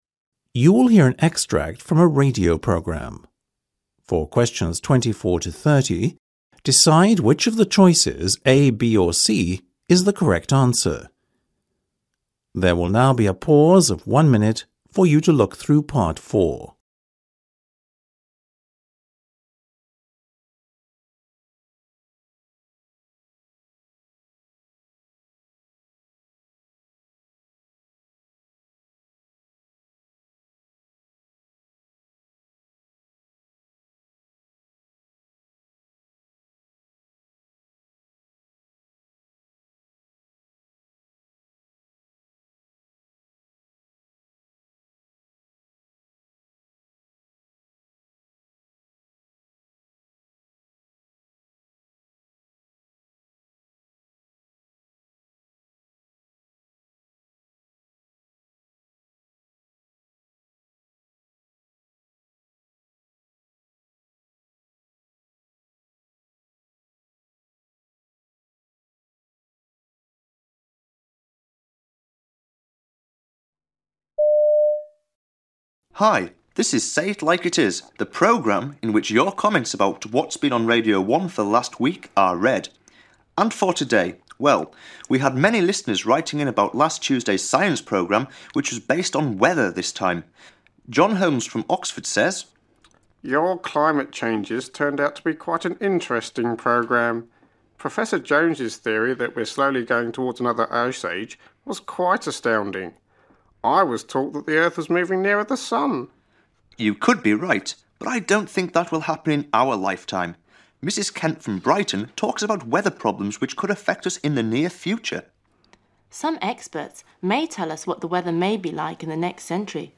You will hear an extract from a radio programme. For questions 24-30, decide which of the choices A, B or C is the correct answer.